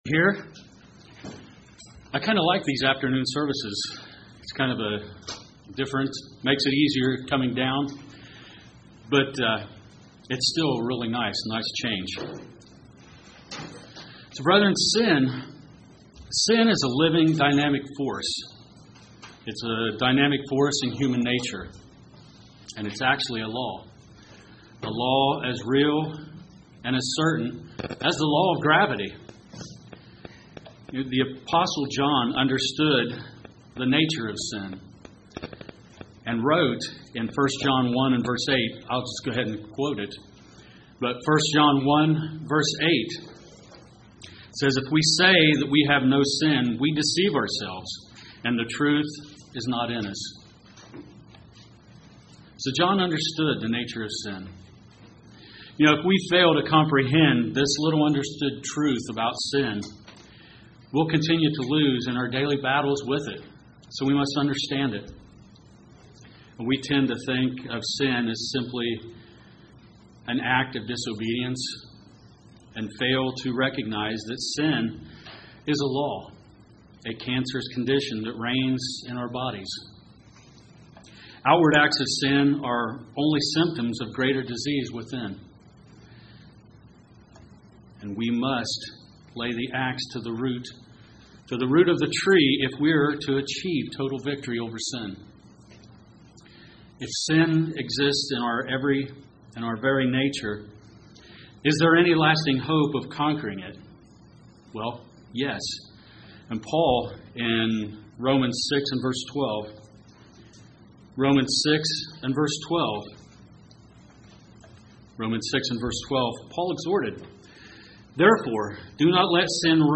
Sermons
Given in Paintsville, KY